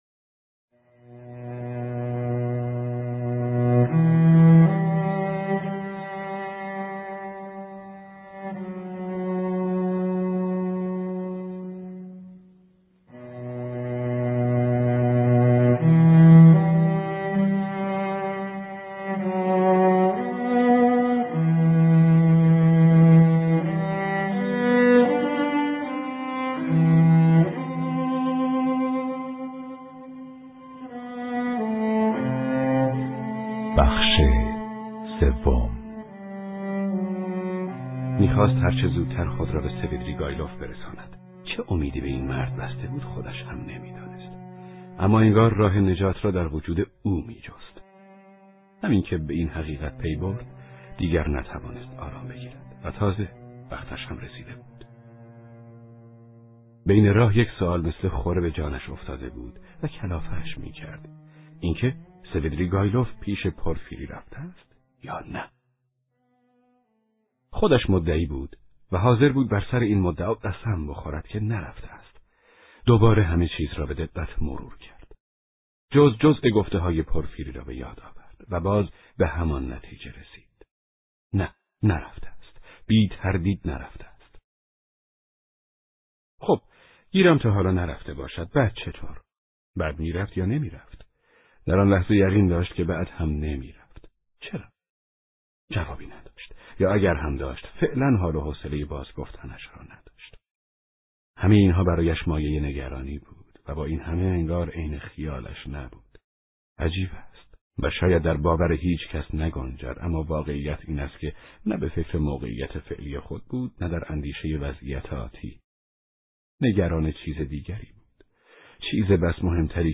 کتاب صوتی جنایت و مکافات (14)